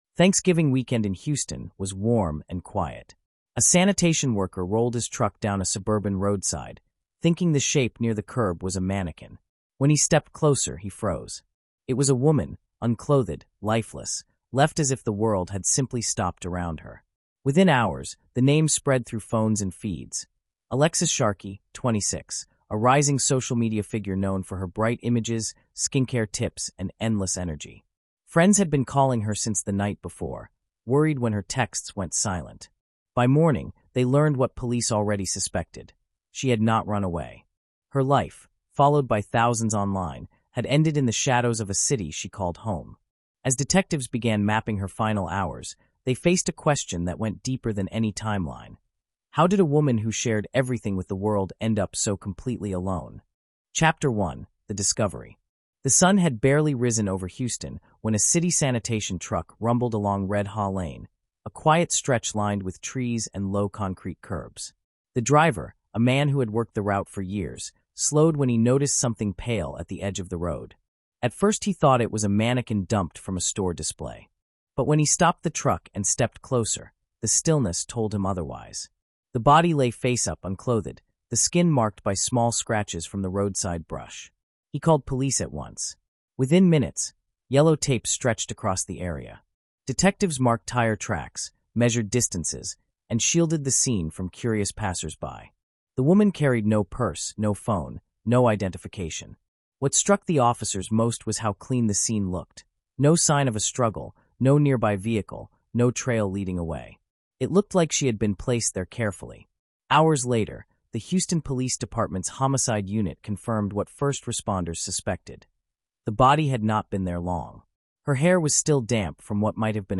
is a cinematic true-crime narrative told in a forensic documentary style